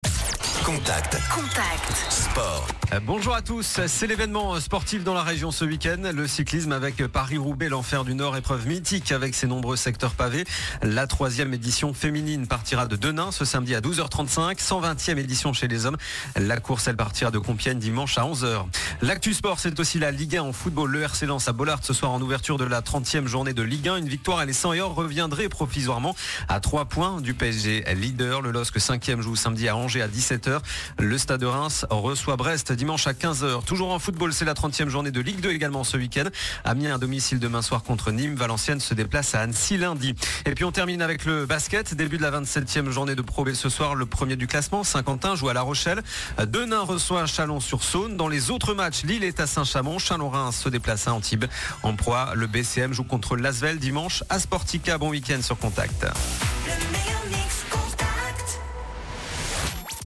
Le journal des sports du 7 avril